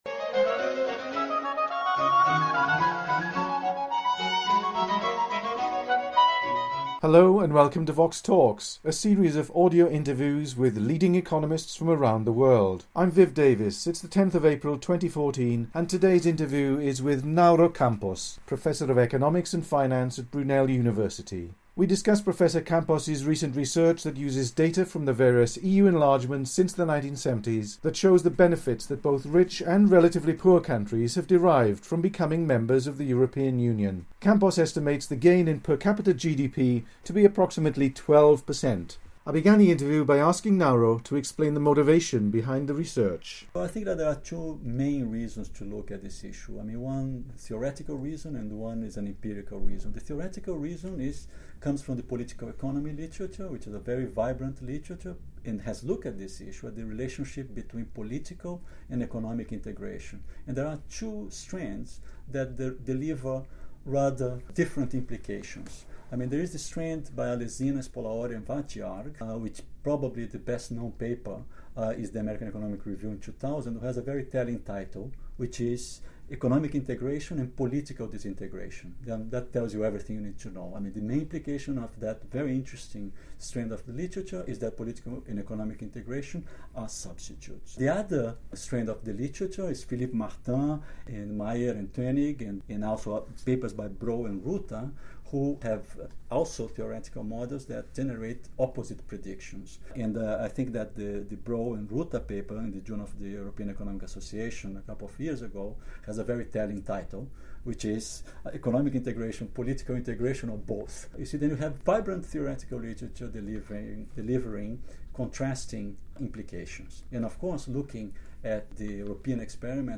The interview was recorded on 10 April 2014.